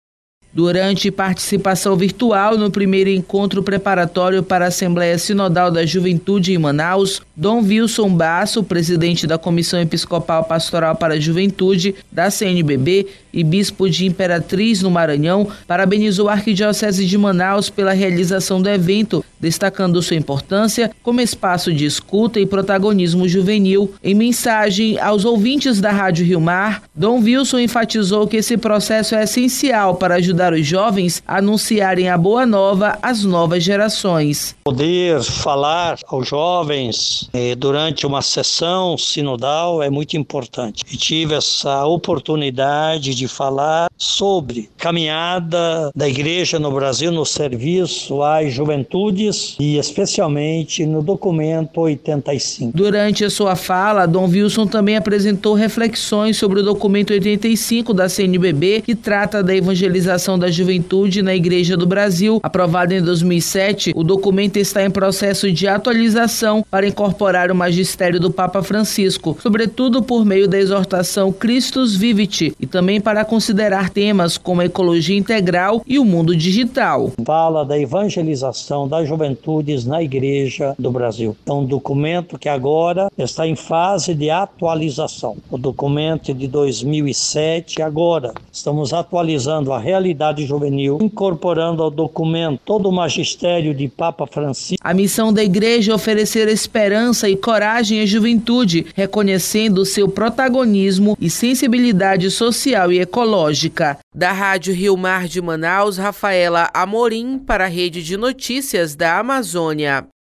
Durante participação virtual no Primeiro Encontro preparatório para a Assembleia Sinodal da Juventude em Manaus, Dom Vilsom Basso, presidente da Comissão Episcopal Pastoral para a Juventude da CNBB e bispo de Imperatriz (MA), parabenizou a Arquidiocese de Manaus (AM), pela realização do evento, destacando sua importância como espaço de escuta e protagonismo juvenil.